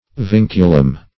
Vinculum \Vin"cu*lum\, n.; pl. L. Vincula, E. Vinculums.